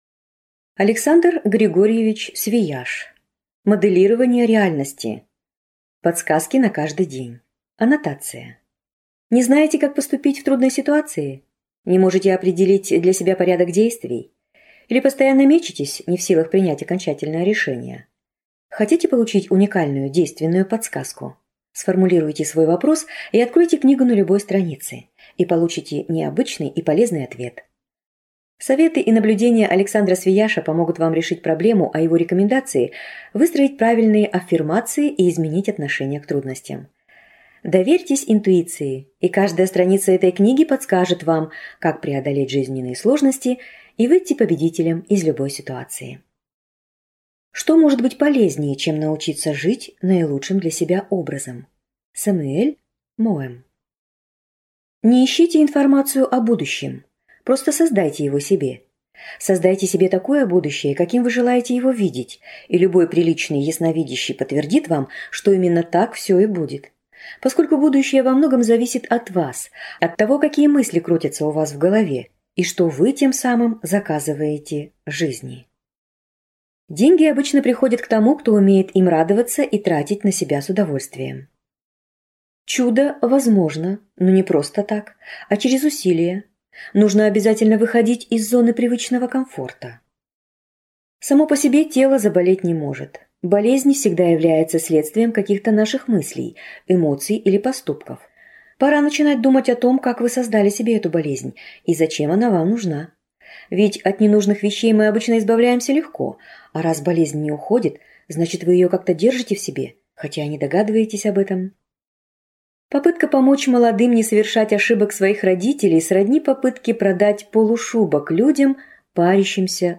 Аудиокнига Моделирование реальности. Подсказки на каждый день | Библиотека аудиокниг